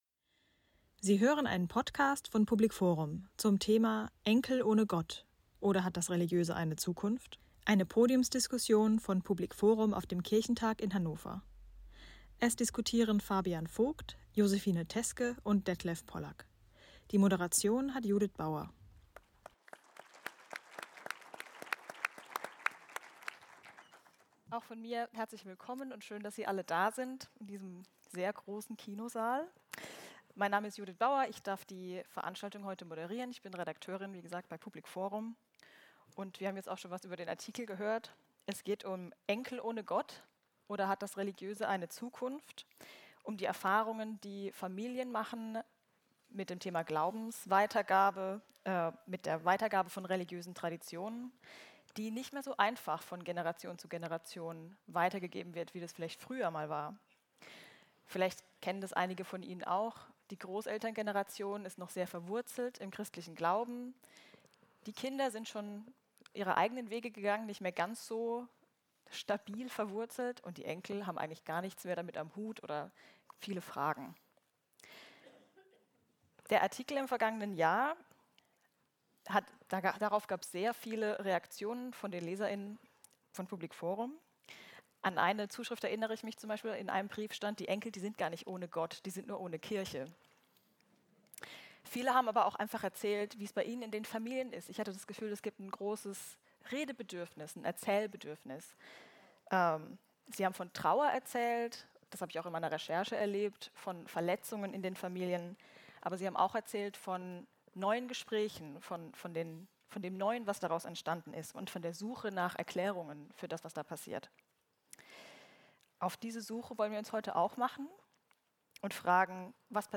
Die Podiumsgäste diskutieren über gelungene Glaubensweitergabe zwischen den Generationen – und welche Rolle die Kirche dabei spielen kann.